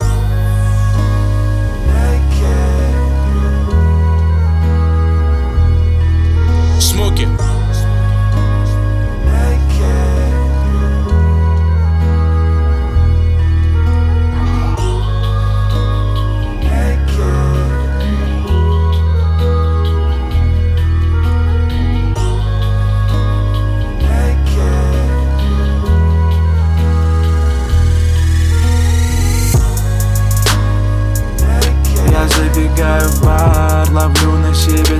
Жанр: Соул / R&b / Русские